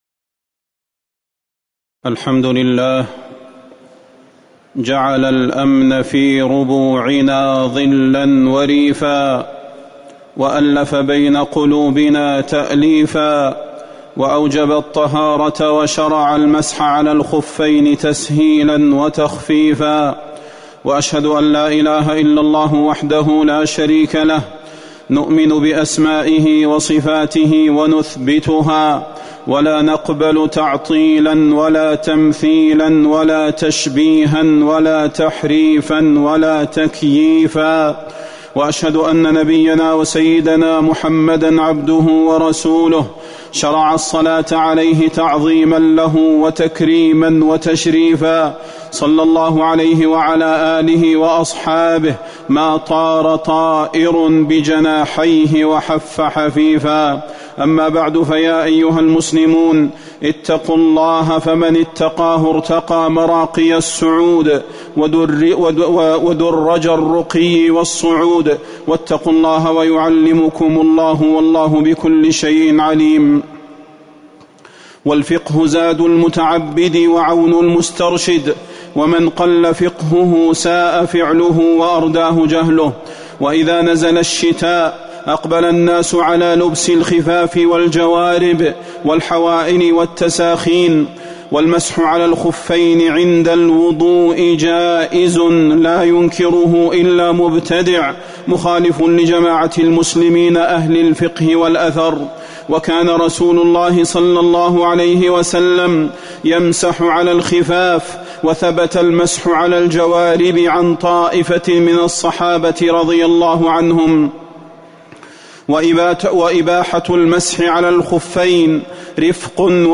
تاريخ النشر ٢ ربيع الثاني ١٤٤١ هـ المكان: المسجد النبوي الشيخ: فضيلة الشيخ د. صلاح بن محمد البدير فضيلة الشيخ د. صلاح بن محمد البدير أحكام المسح على الخفين